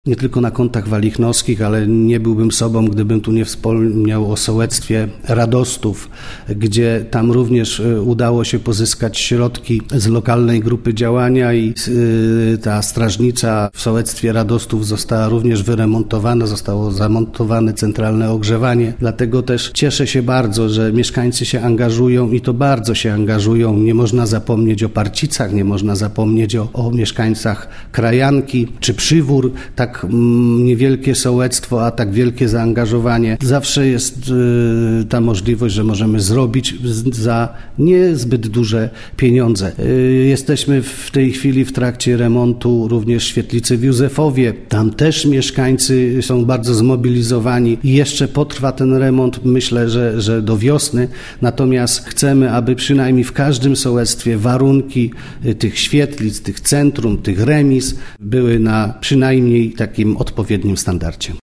– mówił wójt gminy Czastary, Dariusz Rejman.